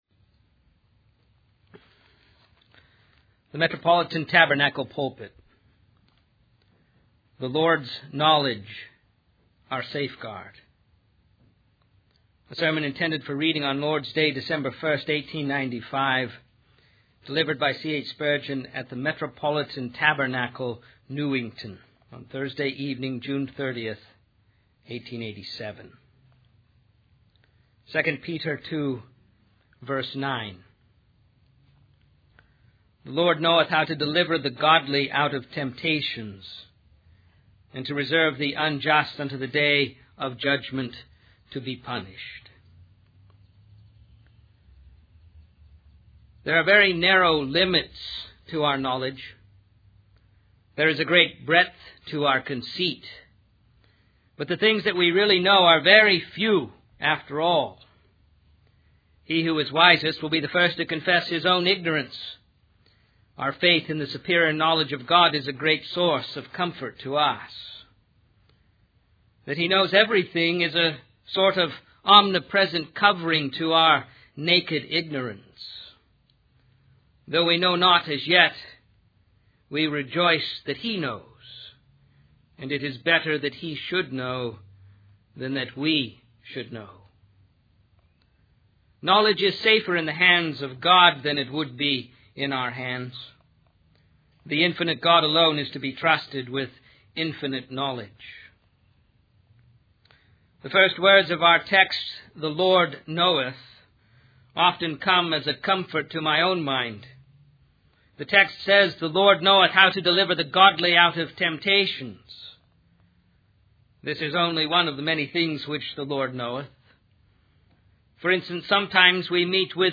In this sermon, the preacher emphasizes the importance of relying on faith rather than relying on what we can see with our physical eyes.